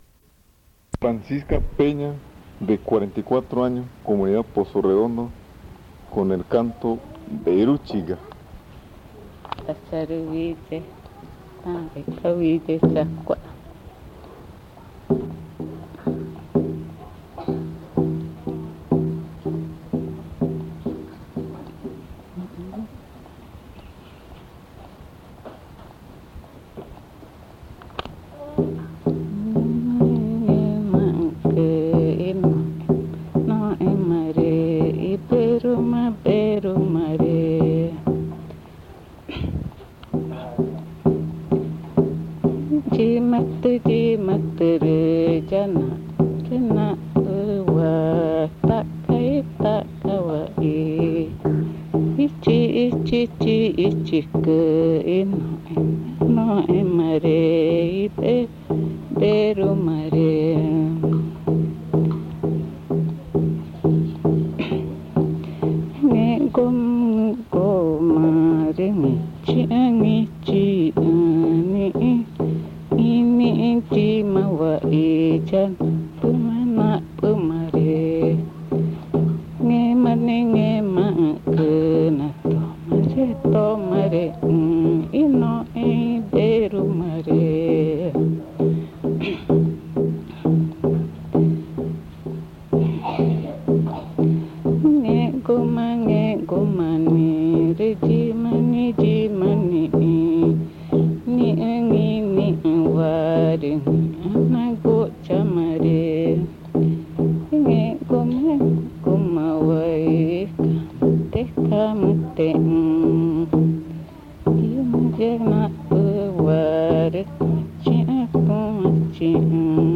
Pozo Redondo, Amazonas (Colombia)